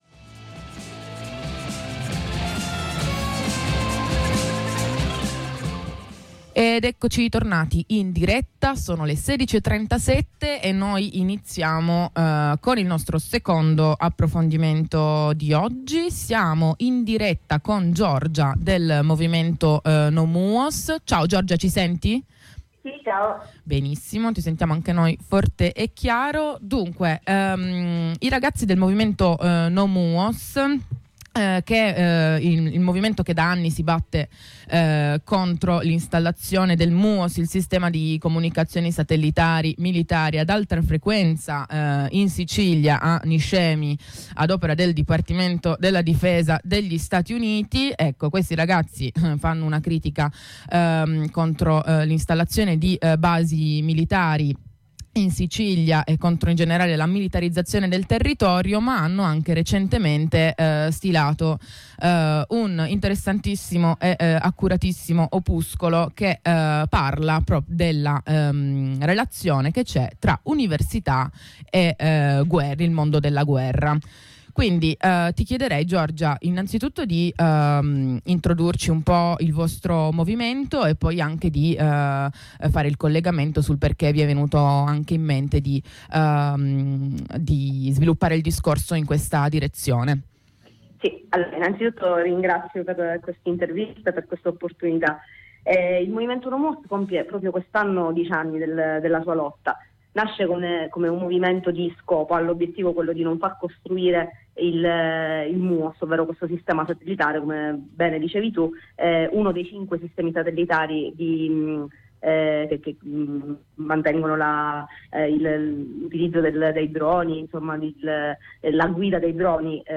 Insieme a una compagna del movimento No MUOS, che si oppone all'installazione di basi militari USA nel territorio siciliano, ripercorriamo gli aspetti più (scaltramente) nascosti e oscuri dei rapporti tra atenei italiani e industria bellica.